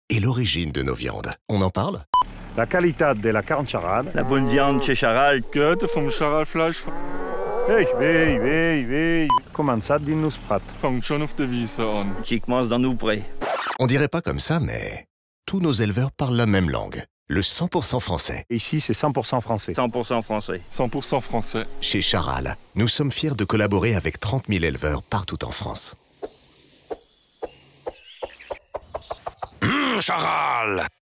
Posé, grave et pointe d'humour.
Spot TV Charal
Imaginé, produit et enregistré chez WPP.
Voix assez grave pour être dans l’univers de la marque avec une pointe d’humour et d’ironie.